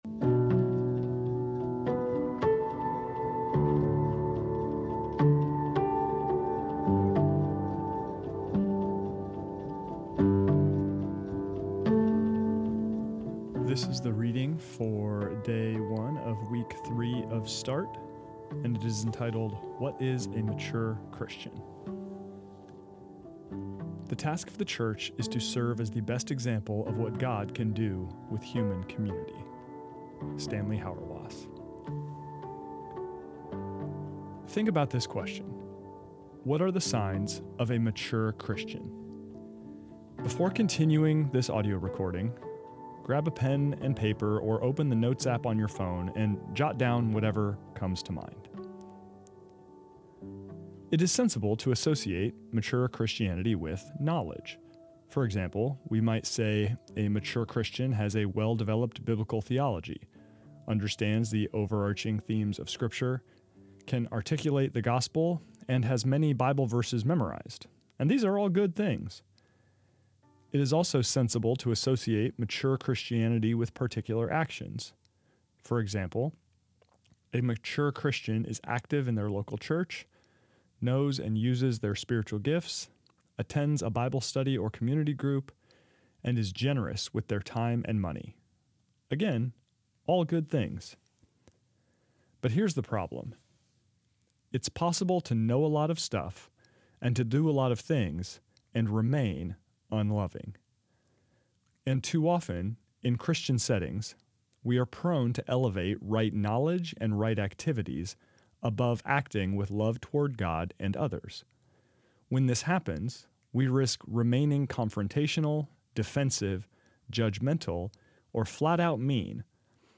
This is the audio recording of the first reading of week three of Start, entitled What is A Mature Christian?